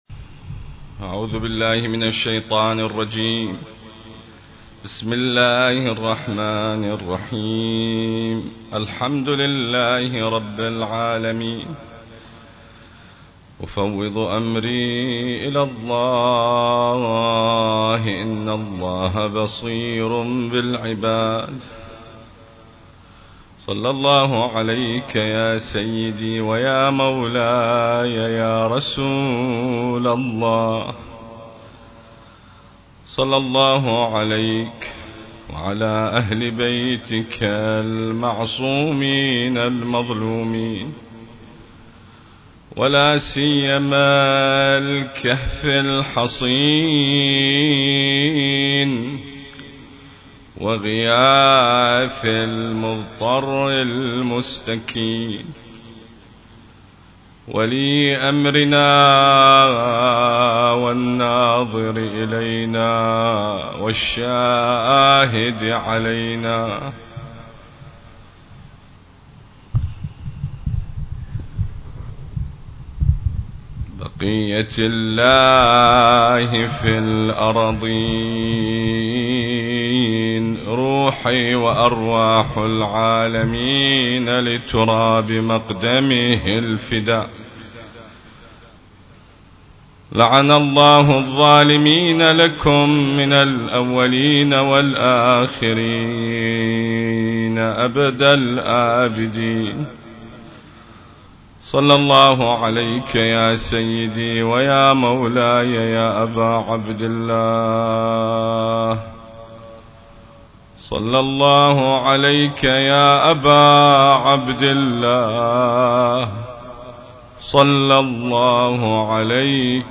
حسينية المرحوم الحاج داود العاشور - البصرة